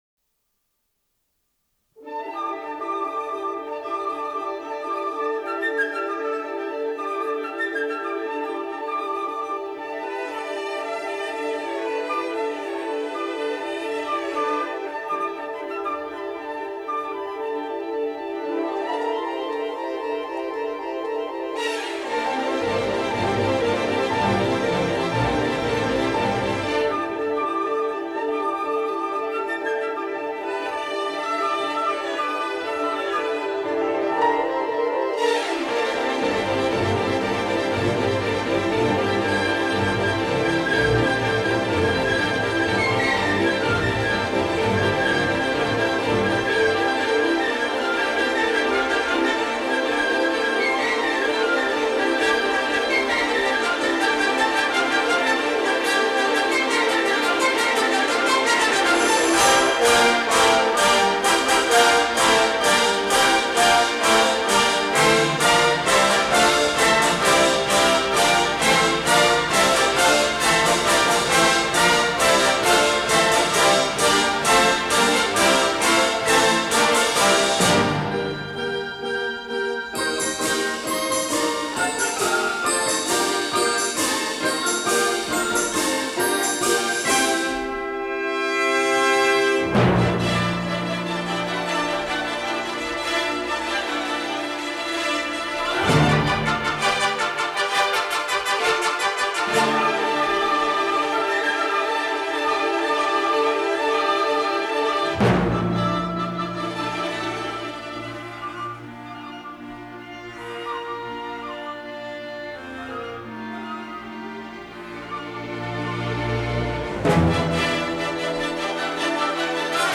Orchestra
Piano
in Symphony Hall, Boston